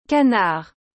Como pronunciar canard corretamente?
O som correto é algo como “ka-nár”, com aquele famoso “r” gutural no final.
canard.mp3